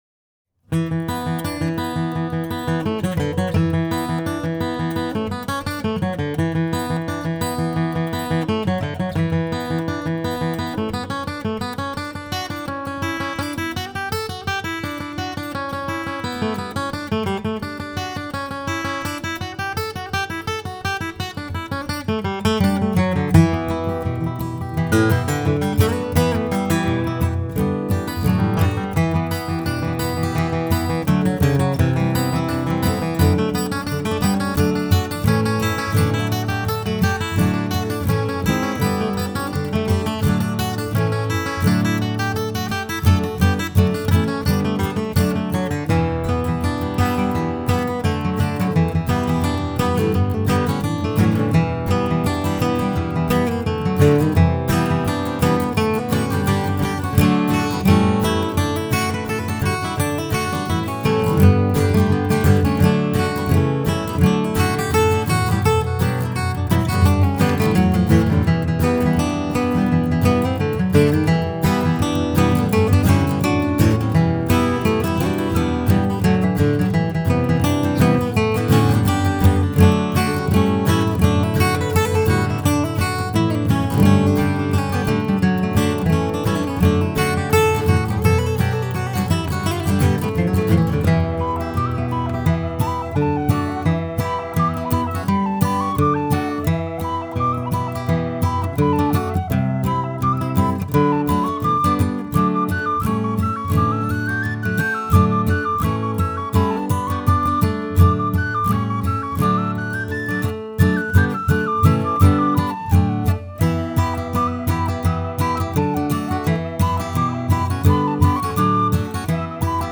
Studio Tunesmith Studio
Notes Traditional Irish reel. Recorded during a session in Nashville.
flatpicked guitars